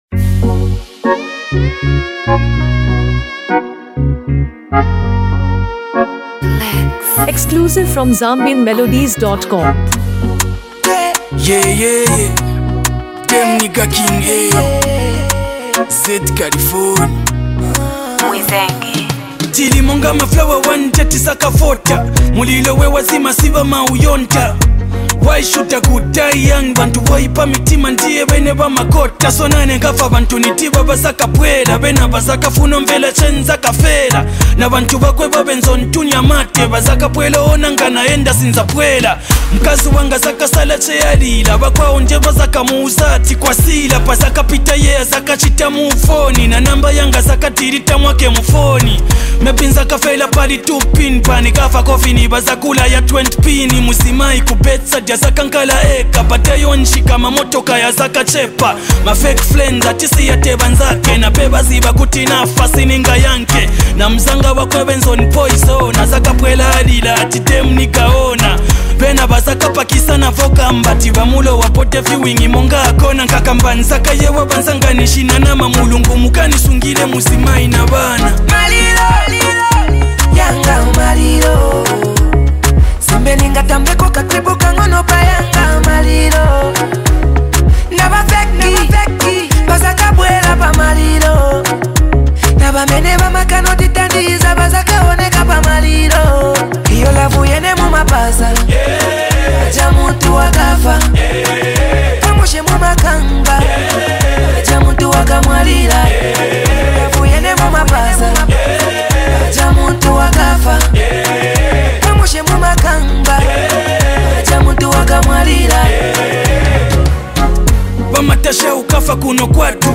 Zambian Music
Through heartfelt lyrics and a soulful beat